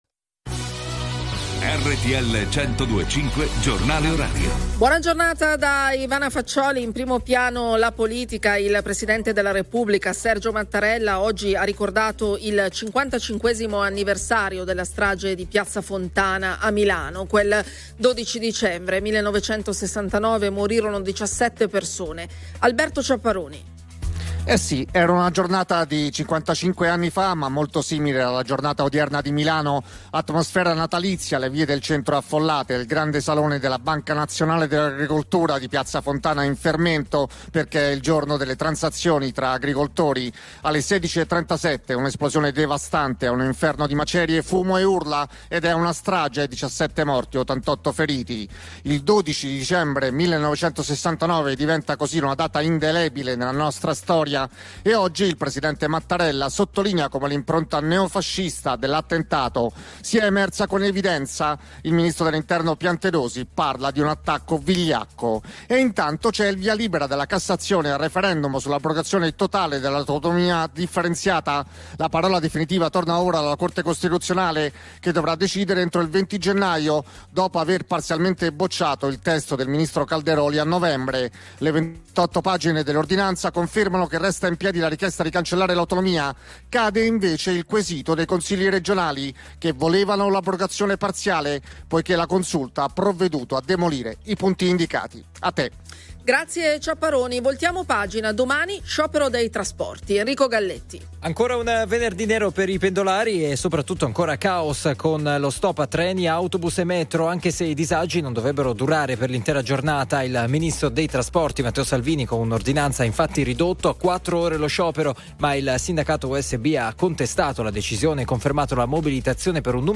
Il giornale orario di RTL 102.5 a cura della redazione giornalistica